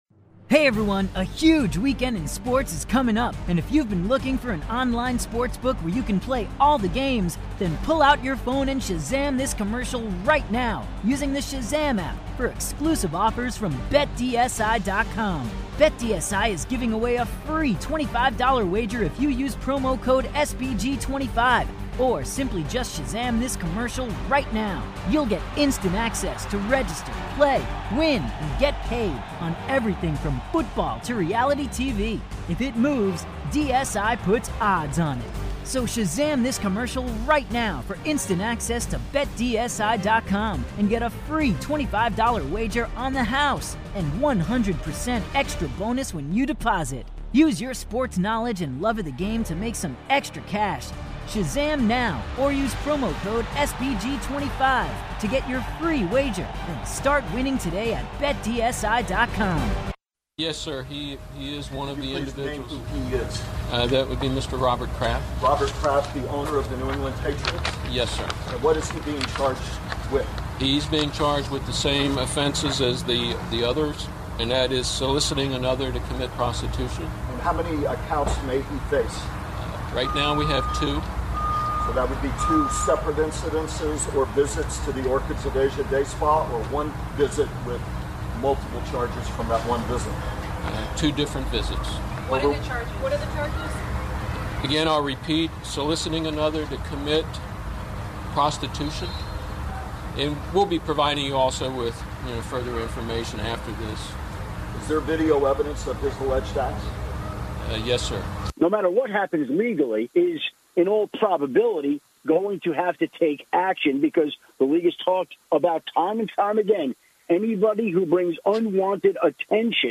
kick off the road show at Big City Wings in Kingwood